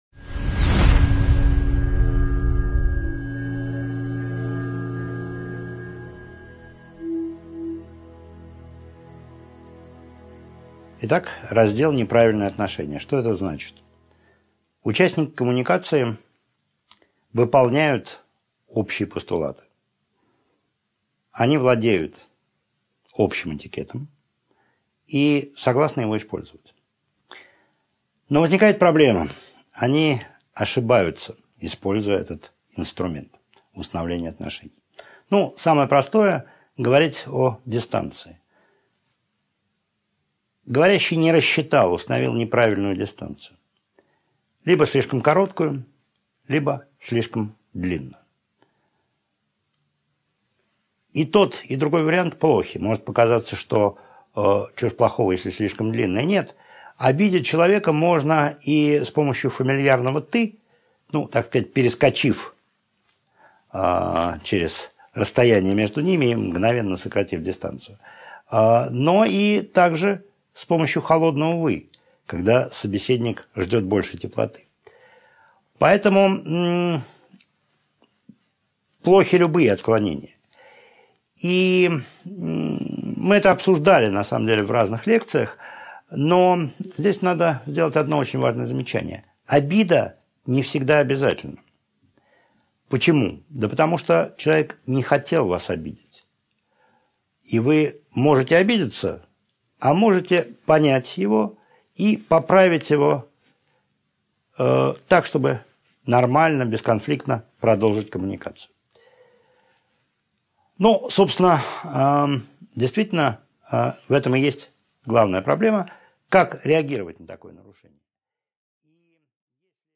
Аудиокнига 9.5 Тонкое несогласие | Библиотека аудиокниг